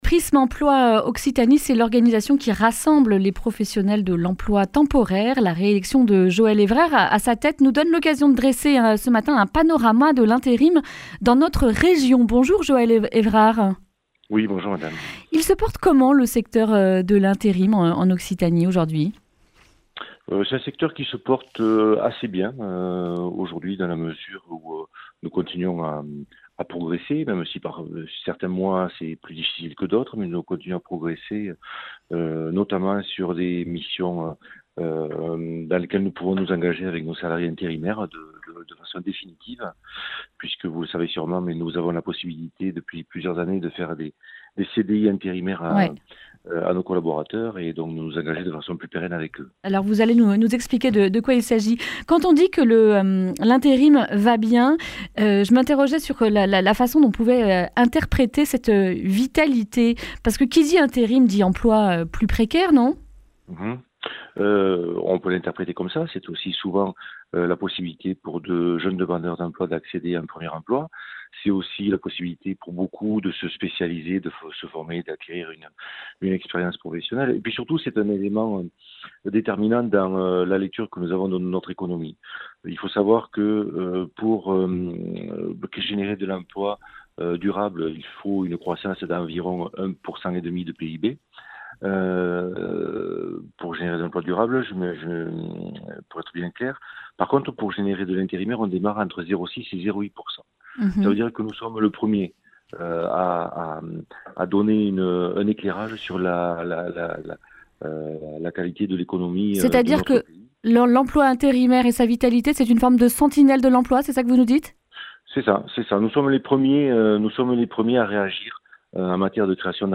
mardi 18 février 2020 Le grand entretien Durée 10 min